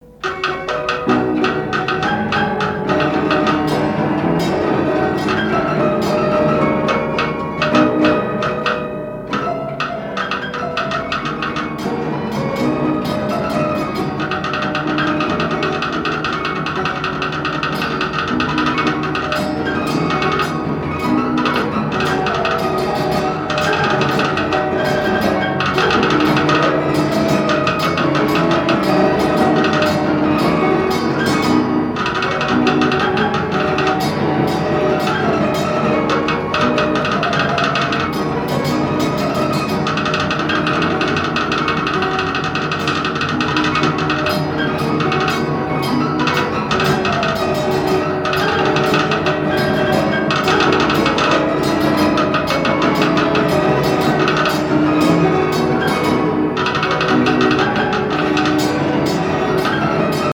piano mécanique
Pièce musicale inédite